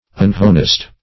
Search Result for " unhonest" : The Collaborative International Dictionary of English v.0.48: unhonest \un*hon"est\, a. Dishonest; dishonorable.